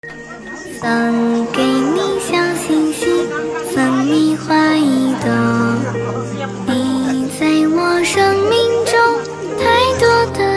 音频：意大利普拉托观音堂佛友们欢聚一堂素食分享会花絮！